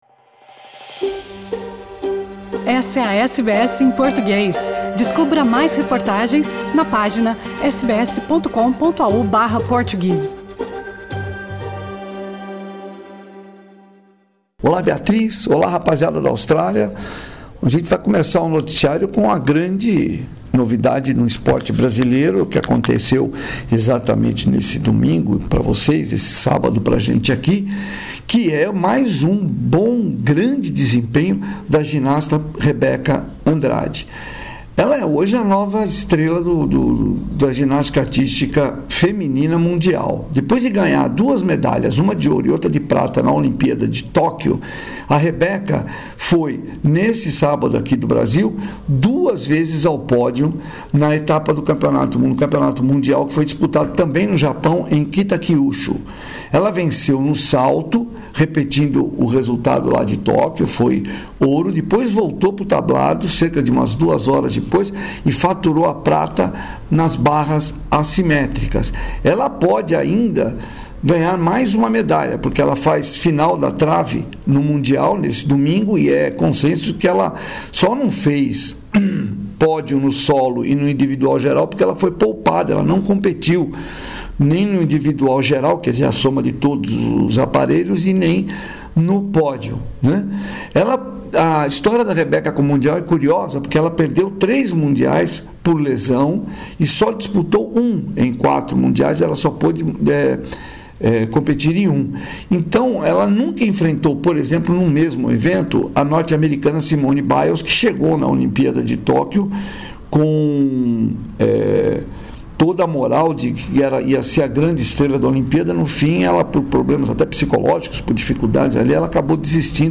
Confira no boletim esportivo desta semana do correspondente da SBS em Português